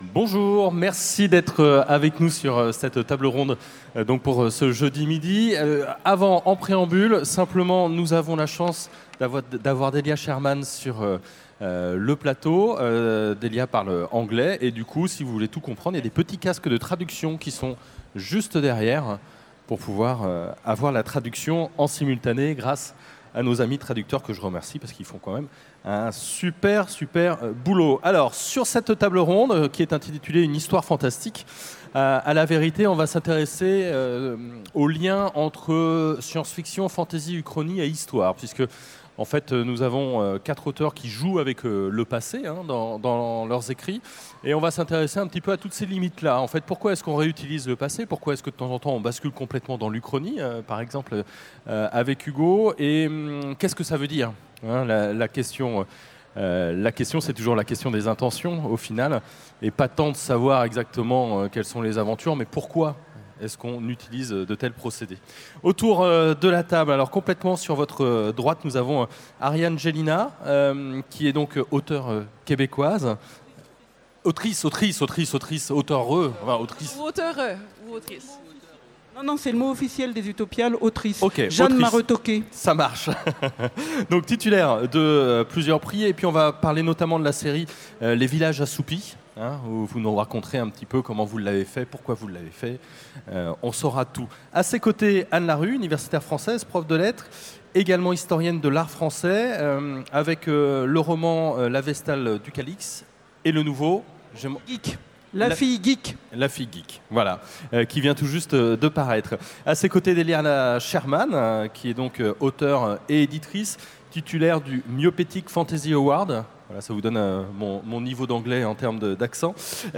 Utopiales 2017 : Conférence Une histoire fantastique